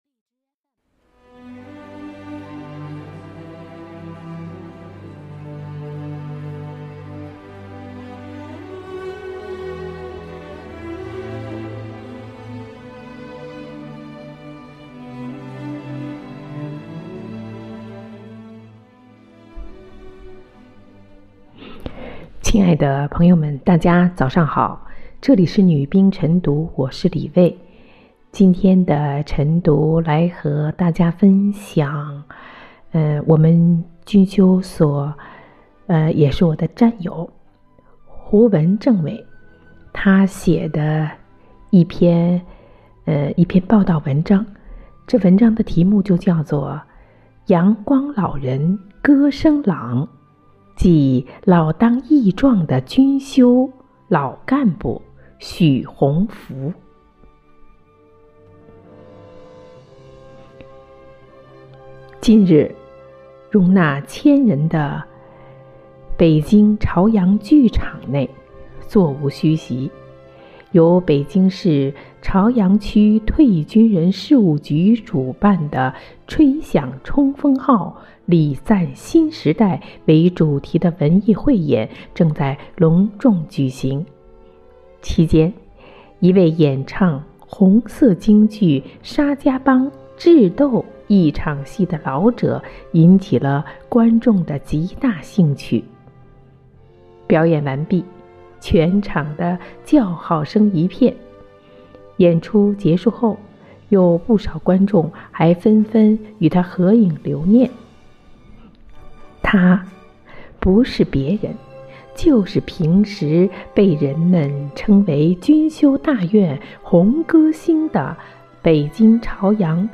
每日《女兵诵读）阳光老人歌声朗